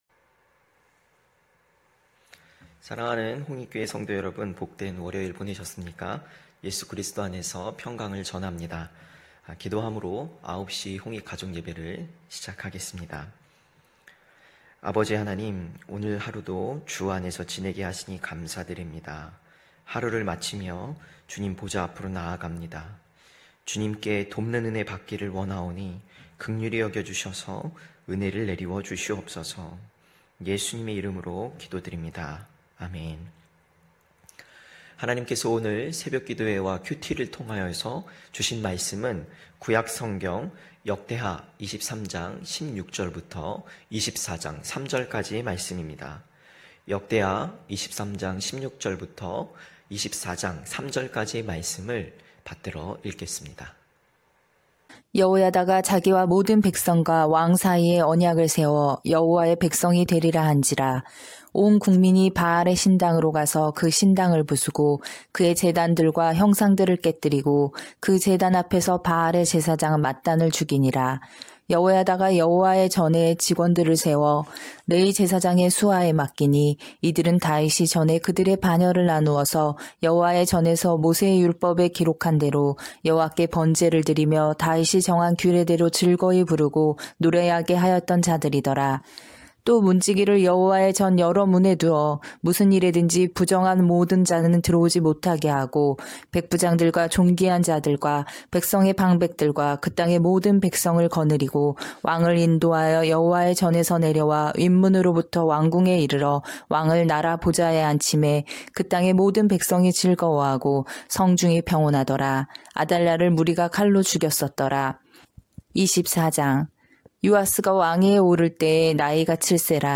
9시홍익가족예배(11월30일).mp3